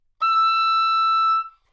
双簧管单音 " 双簧管 D6
描述：在巴塞罗那Universitat Pompeu Fabra音乐技术集团的goodsounds.org项目的背景下录制。
Tag: 好声音 单注 D6 多重采样 纽曼-U87 双簧管